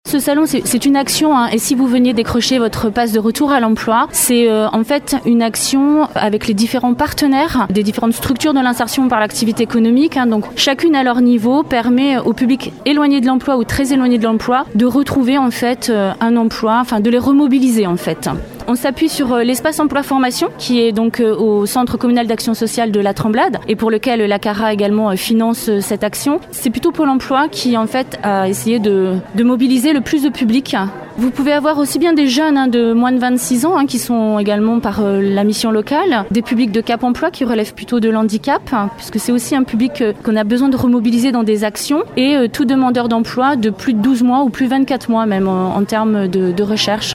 Lors du salon cet après-midi au centre nautique de Ronce-les-Bains.